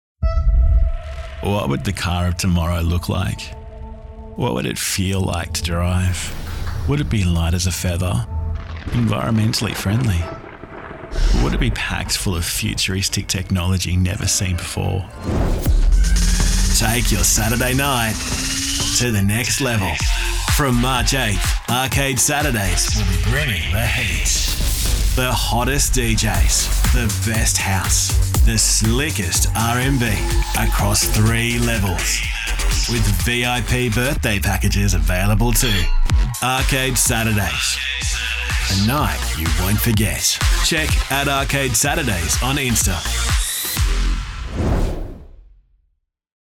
Young and fun, sophisticated and natural, pulled back and versatile….  he’s good to go when you’re ready!
• Smooth Classy